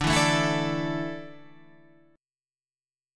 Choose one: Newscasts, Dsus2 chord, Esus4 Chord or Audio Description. Dsus2 chord